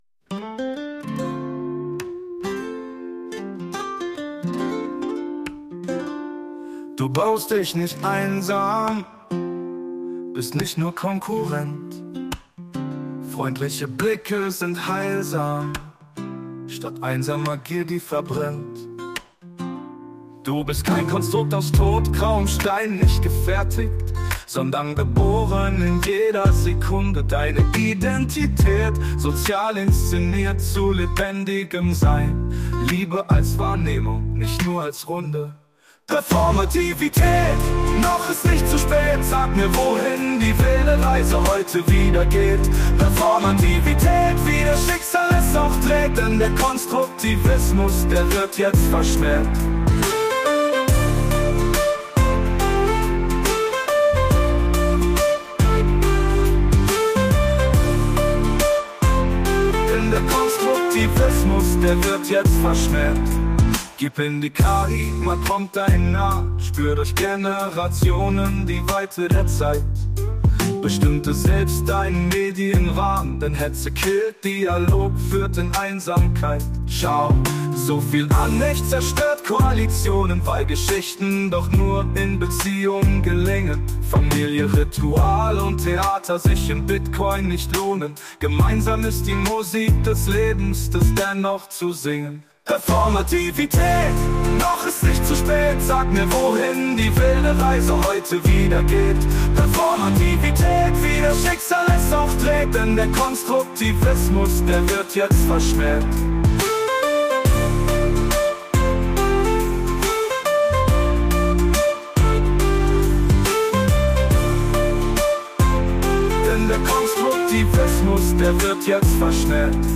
Folge 29 (Bonus): Performativität statt Konstruktivismus (Song)